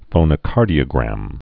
(fōnə-kärdē-ə-grăm)